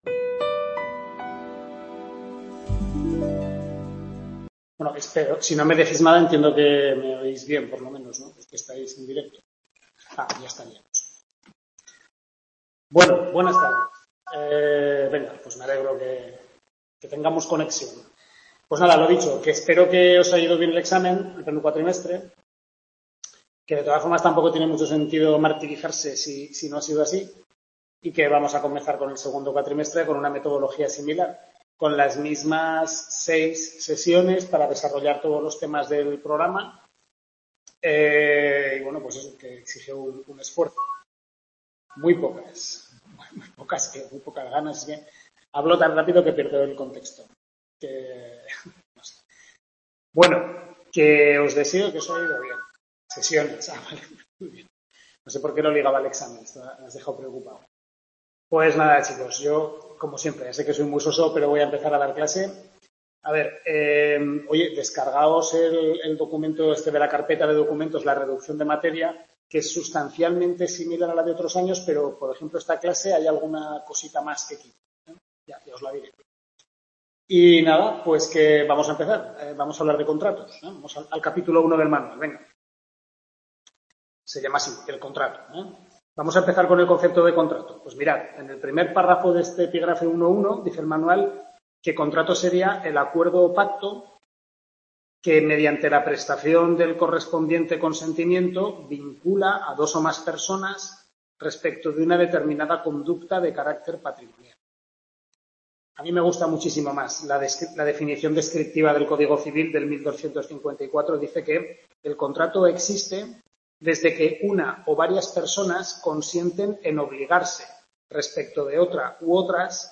Primera tutoría del segundo cuatrimestre de Civil II (Contratos).- Centro UNED Calatayud.